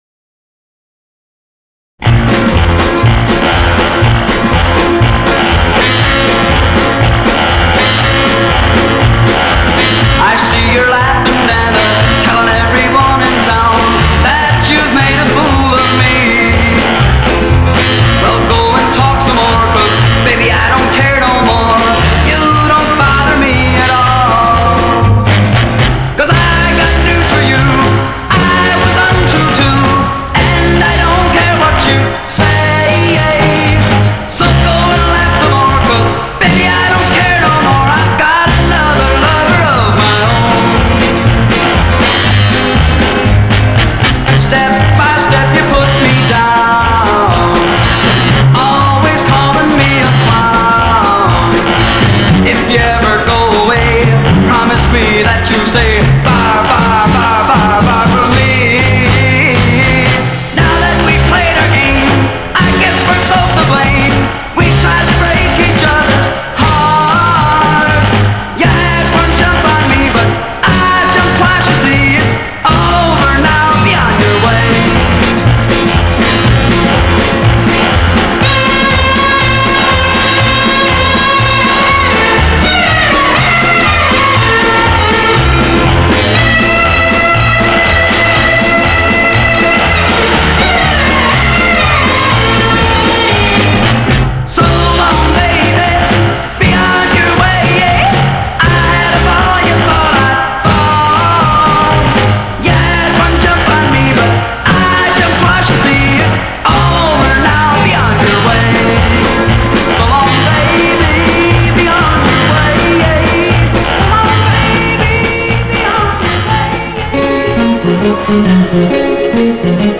I will concentrate on mixes based on these records.